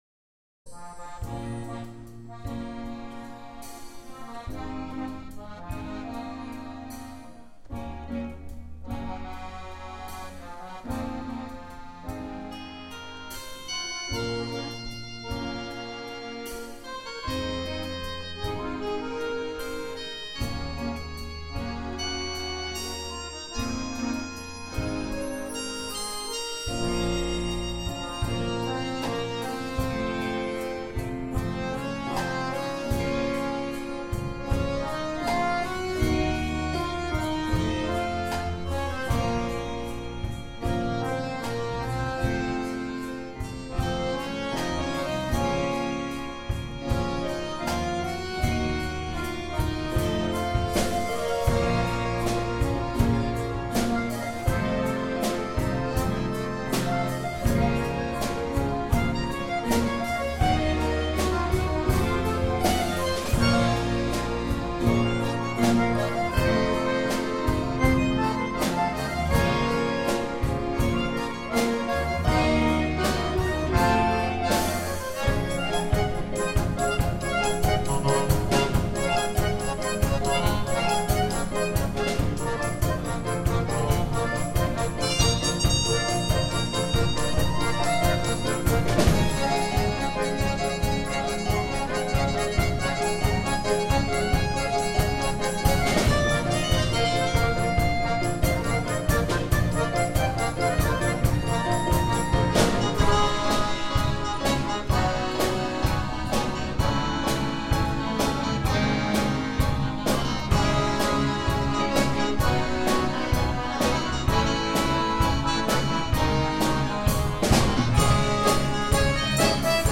2014 – Akkordeonorchester Neustadt bei Coburg e. V.